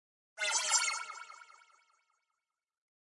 游戏音效 " FX169
描述：爆炸哔哔踢游戏gameound点击levelUp冒险哔哔sfx应用程序启动点击
Tag: 爆炸 单击 冒险 游戏 应用程序 点击的LevelUp 启动 gamesound 哔哔声 SFX